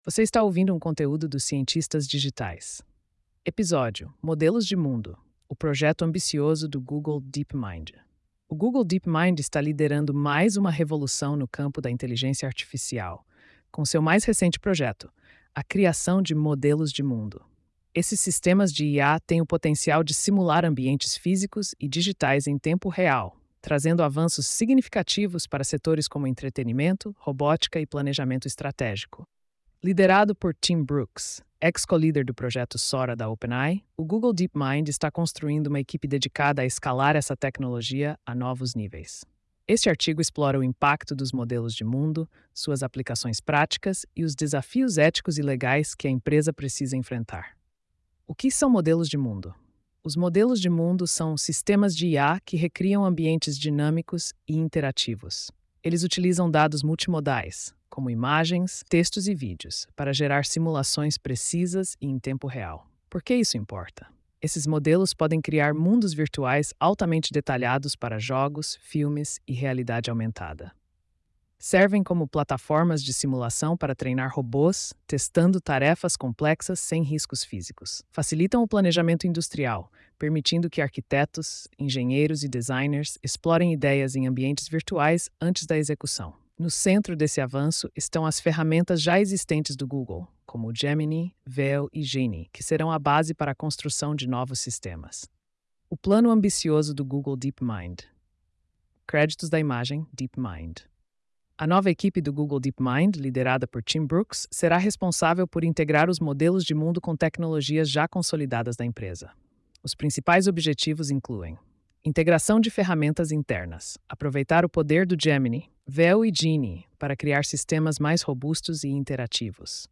post-2666-tts.mp3